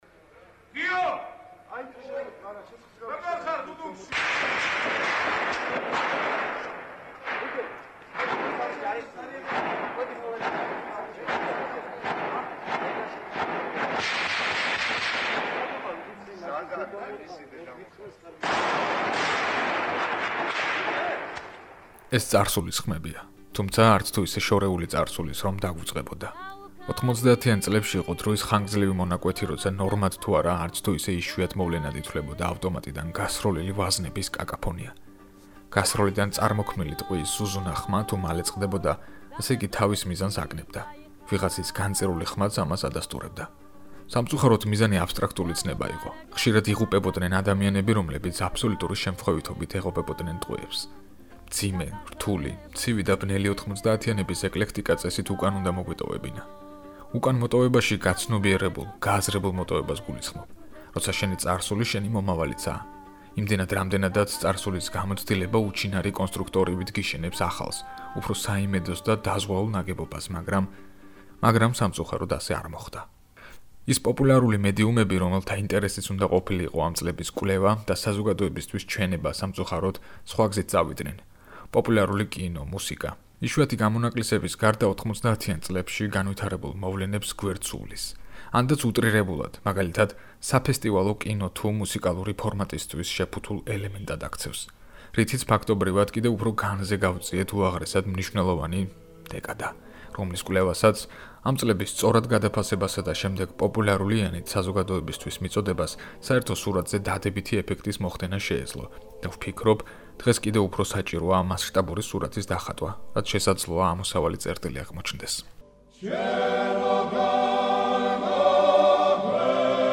ვალსი
სროლის ხმები